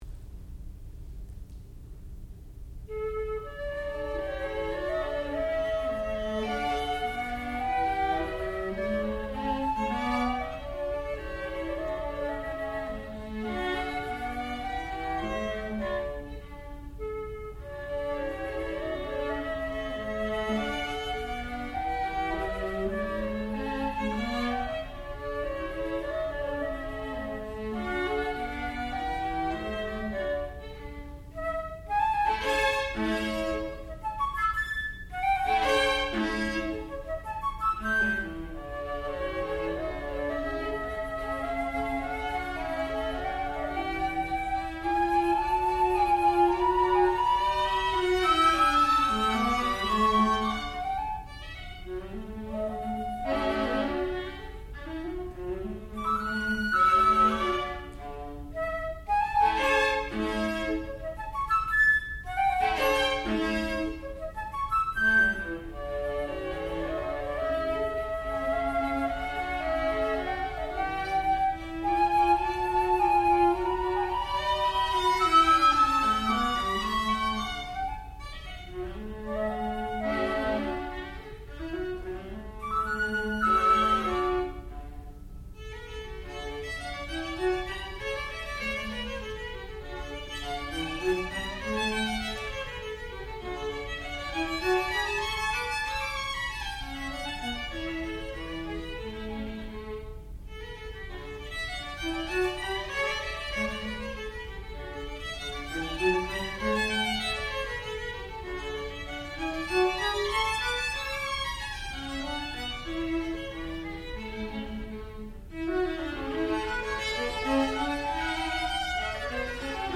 sound recording-musical
classical music
Advanced Degree Recital